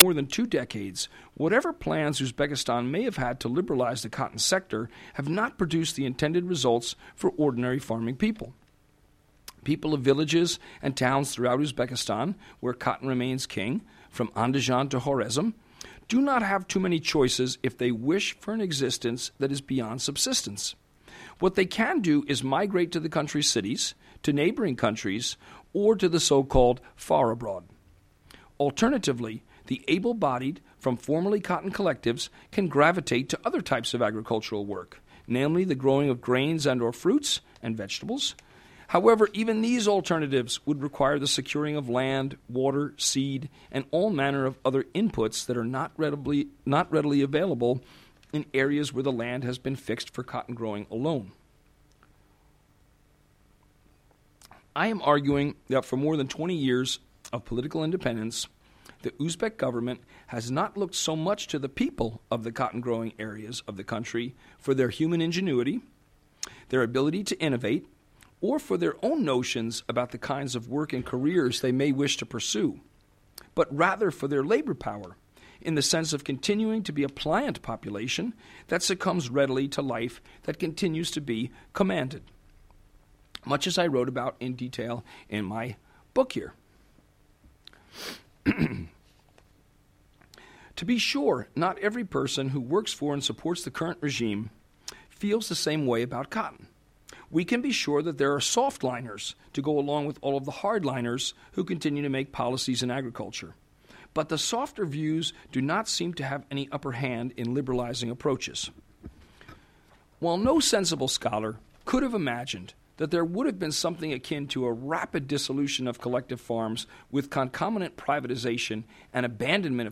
Agriculture/cotton farming in Uzbekistan, Woodrow Wilson Center, Washington, January 17, 2013